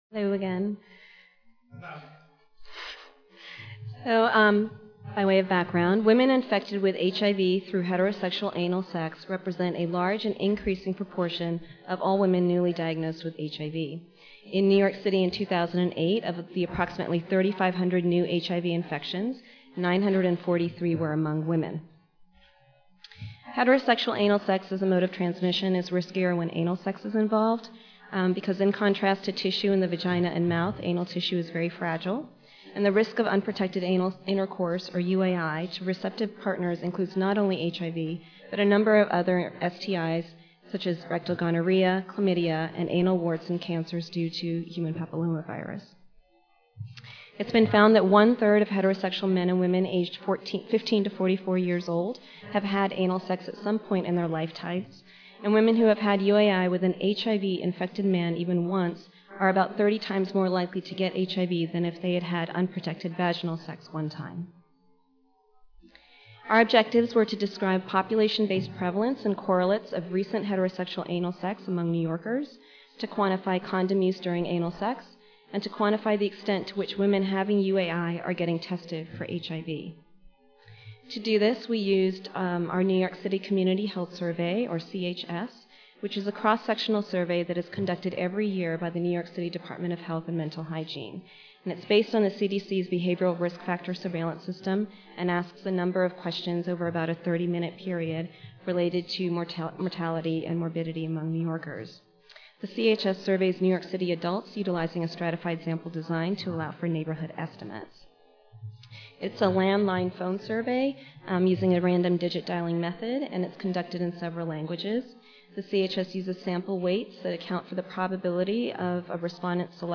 International Ballroom A/B/C/D (M2) (Omni Hotel)
Recorded presentation